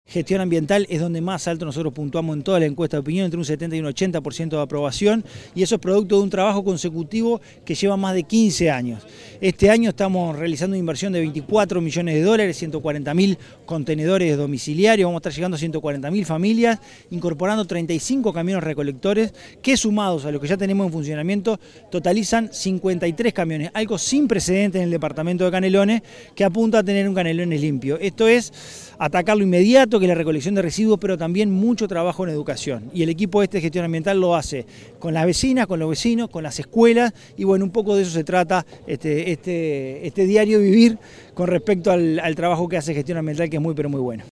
Estuvieron presentes en la entrega el Secretario General de la Intendencia de Canelones, Dr. Esc Francisco Legnani, el Director General de Gestión Ambiental, Leonardo Herou, el Alcalde del Municipio de Progreso, Claudio Duarte, vecinas y vecinos de la zona.